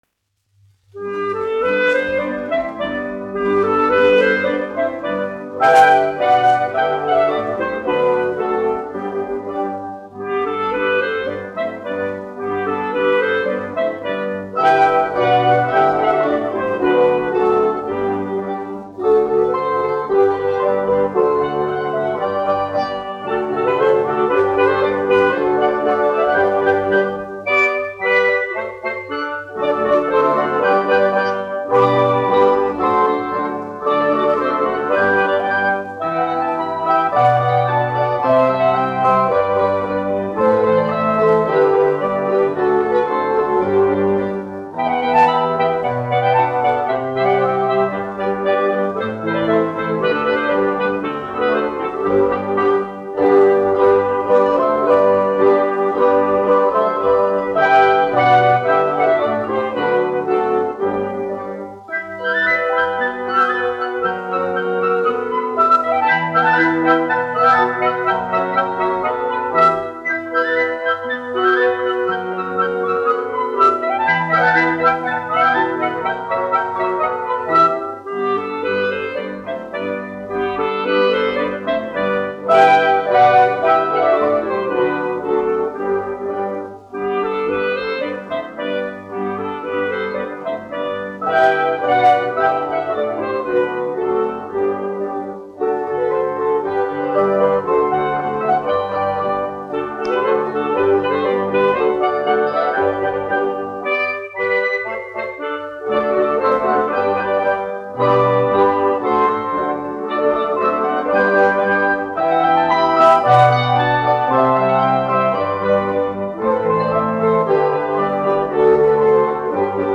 Kamoliņu deja : latviešu tautas deja
1 skpl. : analogs, 78 apgr/min, mono ; 25 cm
Latviešu tautas dejas
Latvijas vēsturiskie šellaka skaņuplašu ieraksti (Kolekcija)